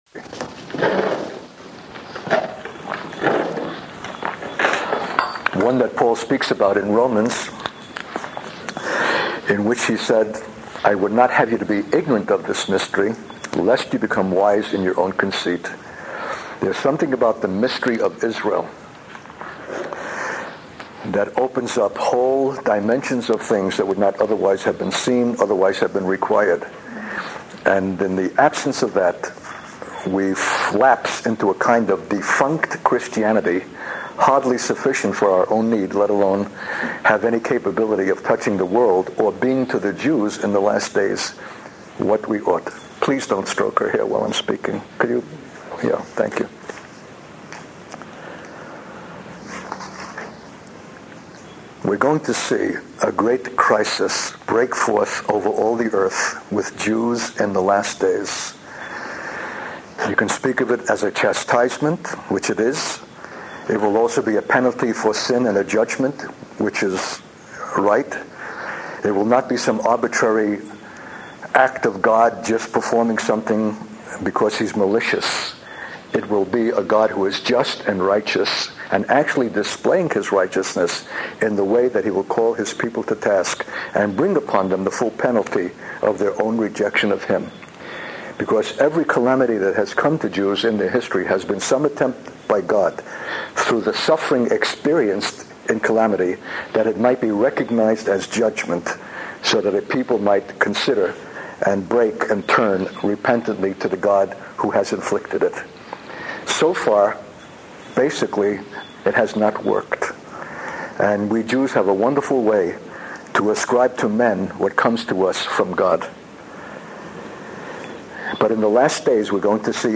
In this sermon, the speaker discusses the current circumstances in Israel and how they are setting the stage for future events. He mentions that the violence that will break out in Israel will not be confined to the country but will also affect Jews worldwide.